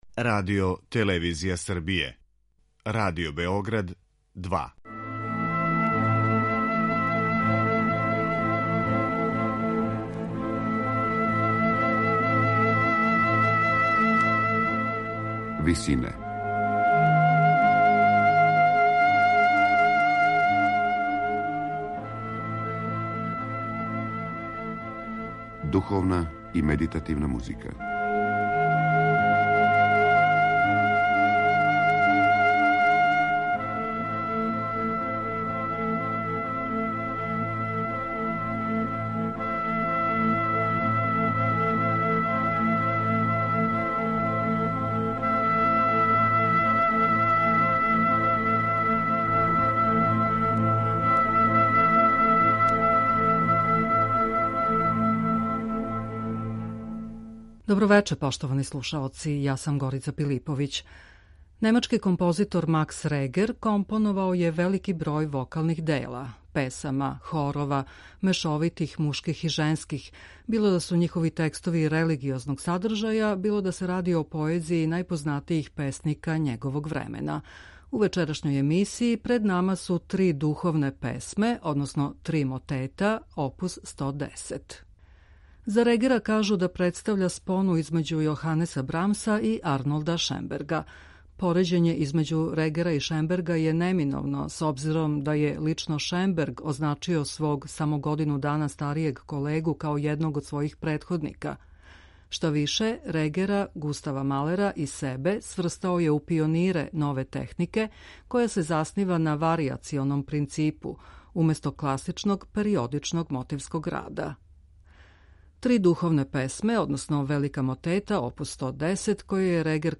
три мотета
Духовни амбис, анксиозност и сумња воде до правих музичких искушења где емотивно набијен контрапункт постаје парализован у густој, комплексној фактури. Изненада ти замршени чворови се развезују и претапају у нежне унисонe фразе које је тешко надмашити.